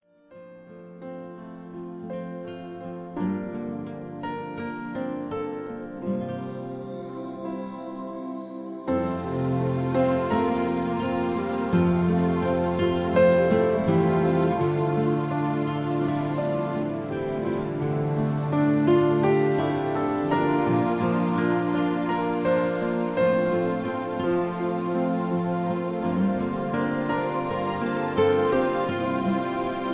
(Key-F, Tono de F)